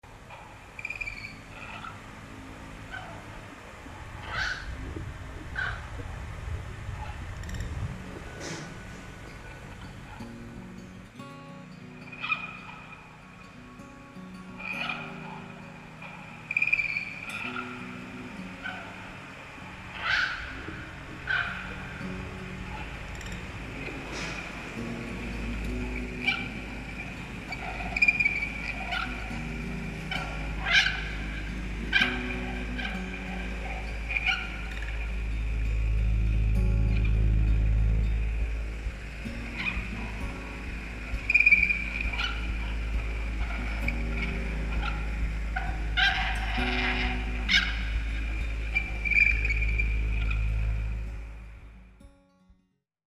The Parrots Have Fan 🦜🦜 Sound Effects Free Download